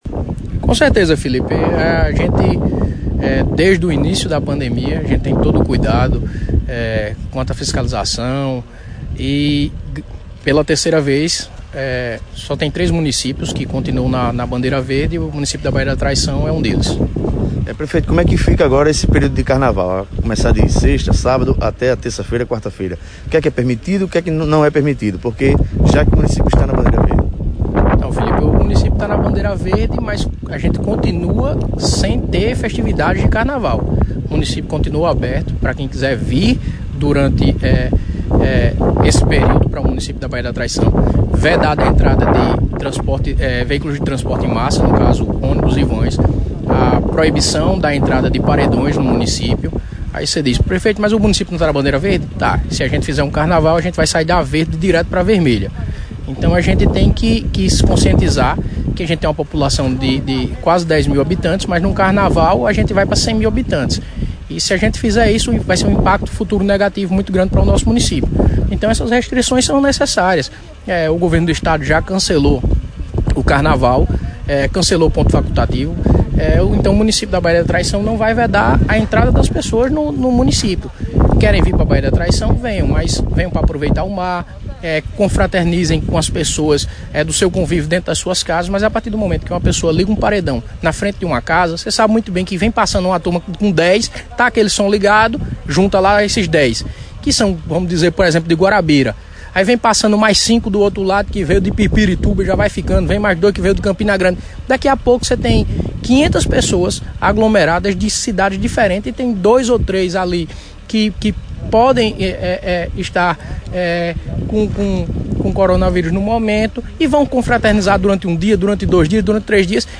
O prefeito Serginho Lima (DEM) da Baía da Traição concedeu entrevista exclusiva ao Programa Panorama 104 (Rádio Litoral Norte 104,9 Fm), e ao Portal de Notícias PBVale, e esclareceu pontos referente a proibição de eventos, bandeira verde e etapas de vacinação contra a Covid-19 no município.